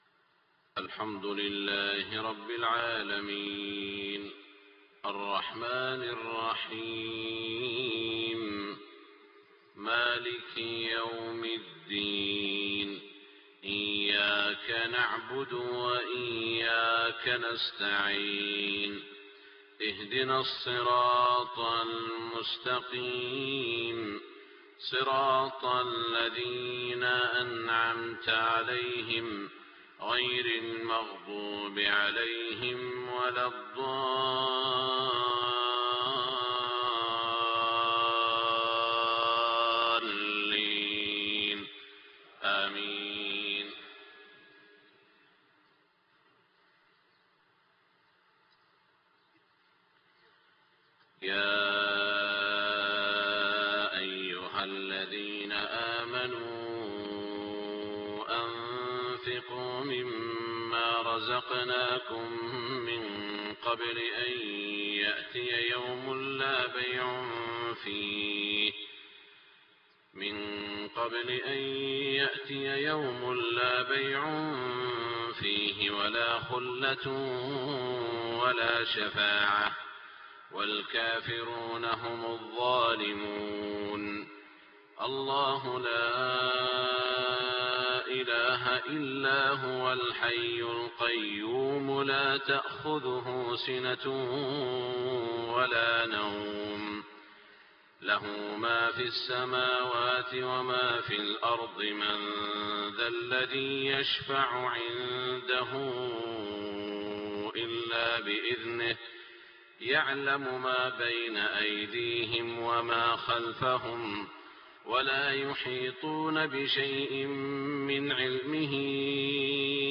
صلاة الفجر 25 شعبان 1427هـ من سورة البقرة > 1427 🕋 > الفروض - تلاوات الحرمين